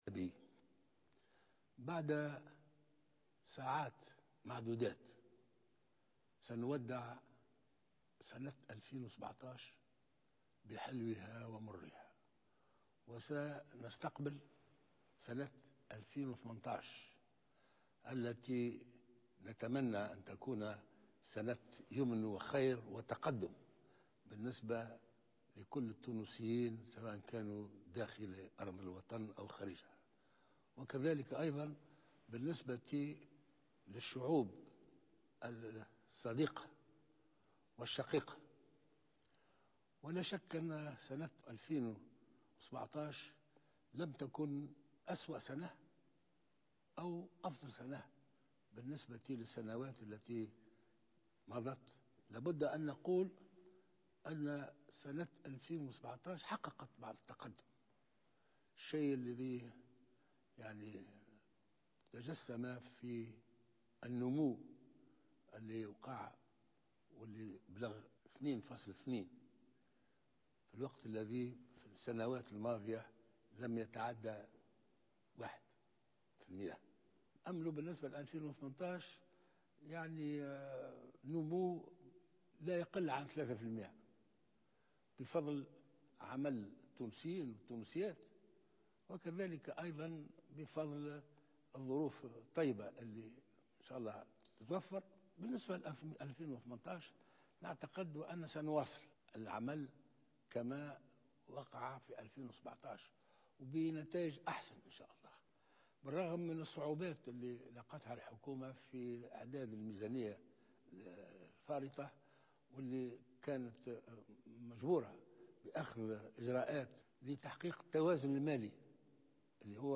قال رئيس الجمهورية الباجي قايد السبسي في كلمة توجه بها الى الشعب التونسي مساء الأحد بمناسبة السنة الإدارية الجديدة أن سنة 2018 ستكون سنة فارقة رغم أن سنة 2017 حققت بعض التقدم.